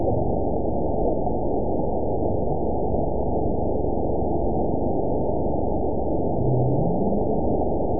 event 917165 date 03/22/23 time 17:31:30 GMT (2 years, 1 month ago) score 9.31 location TSS-AB01 detected by nrw target species NRW annotations +NRW Spectrogram: Frequency (kHz) vs. Time (s) audio not available .wav